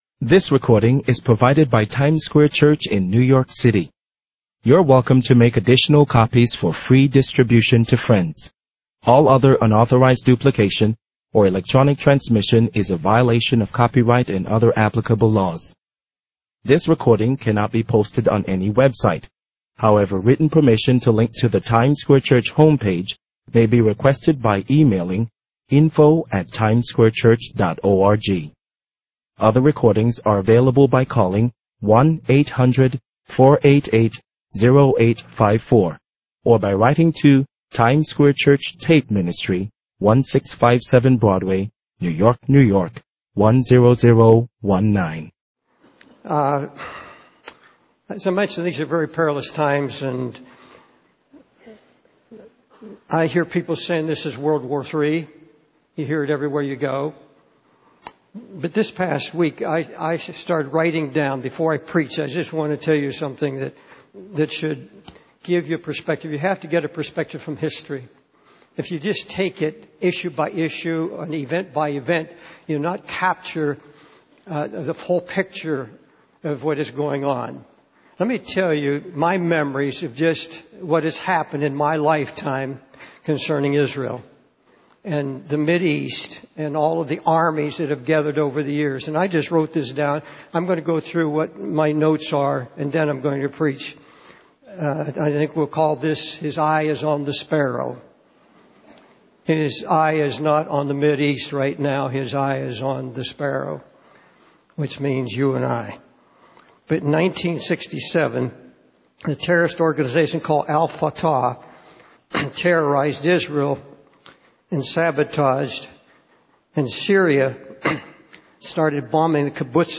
In this sermon, the preacher talks about the presence of Christ and how the Lord spoke to him.